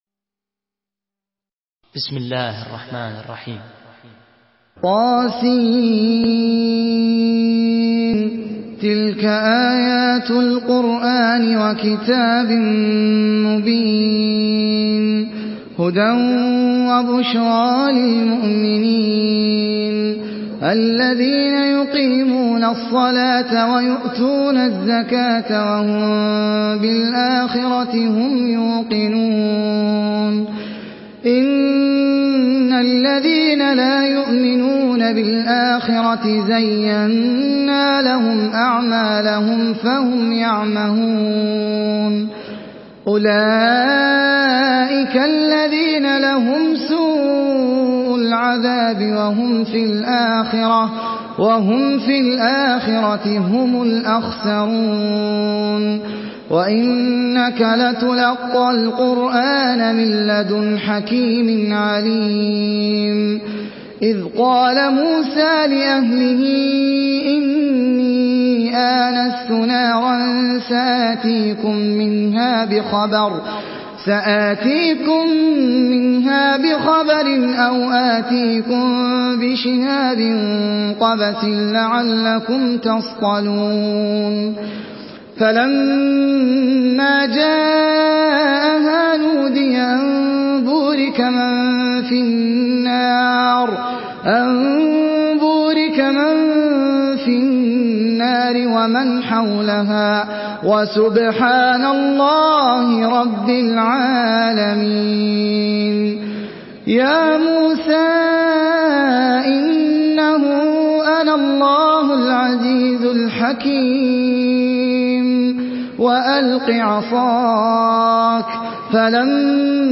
Surah Neml MP3 in the Voice of Ahmed Al Ajmi in Hafs Narration
Murattal Hafs An Asim